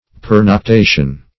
Search Result for " pernoctation" : The Collaborative International Dictionary of English v.0.48: Pernoctation \Per`noc*ta"tion\, n. [L. pernoctatio, fr. pernoctare to stay all night; per + nox, noctis, night.]
pernoctation.mp3